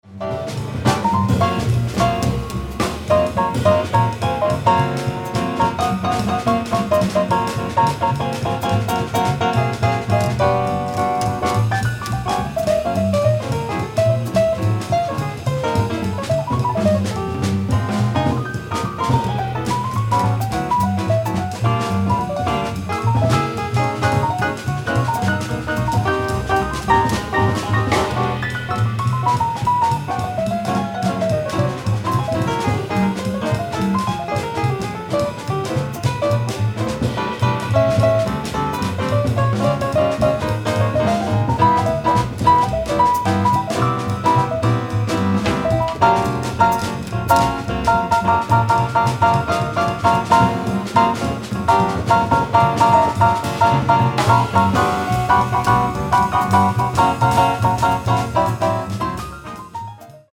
piano
alto saxophone
acoustic bass
drums
modern mainstream jazz